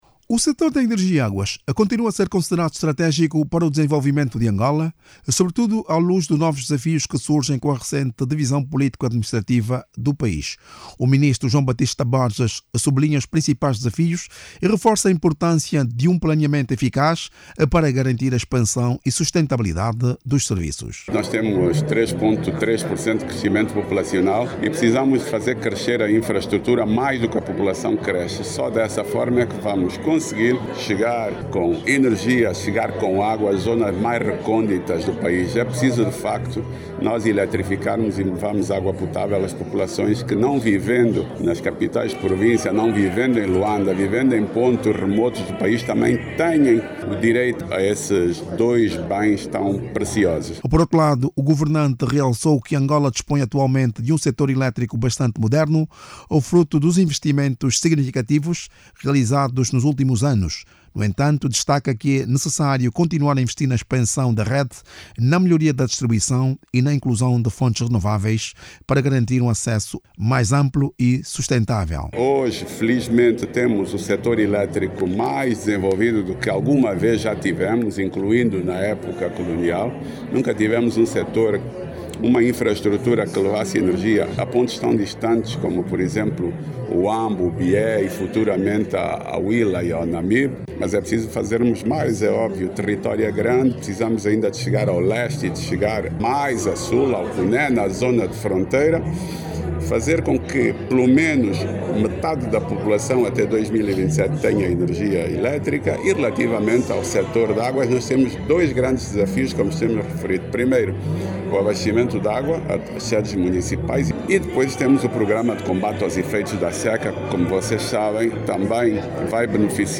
O Ministro da Energia e Águas, João Baptista Borges, garante que o seu sector tem as estratégias traçadas para a melhoria do fornecimento destes dois serviços a população. Em entrevista à margem da cerimónia de condecorações, João Baptista Borges assegurou que o seu ministério está a desenvolver acções nos domínios da energia e das águas, para estes dois serviços cheguem em zonas de difícil acesso.